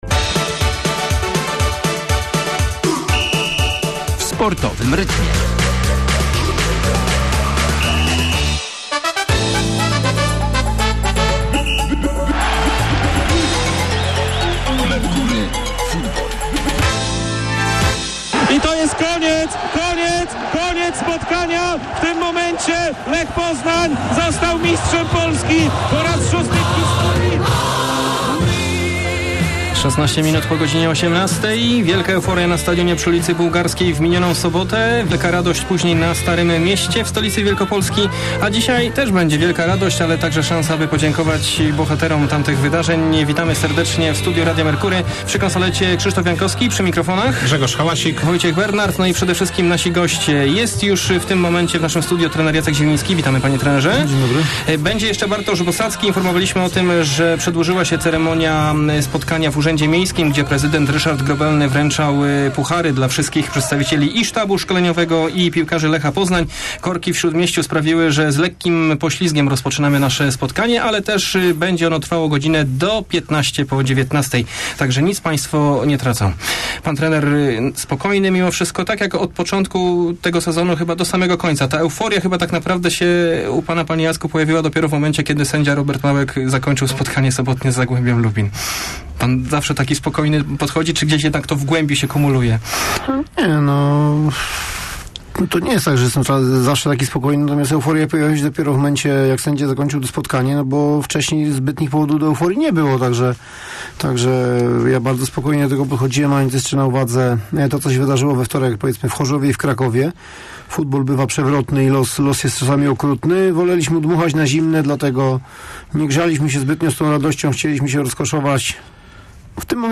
Trener Lecha Jacek Zieliński oraz kapitan Kolejorza - Bartosz Bosacki byli gośćmi audycji "Mistrzowie bez tajemnic". Gratulacje za zdobycie tytułu składali także Państwo.